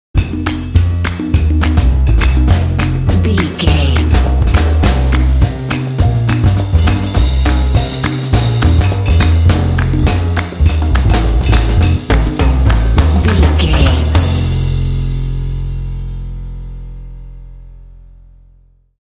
Aeolian/Minor
groovy
sultry
drums
piano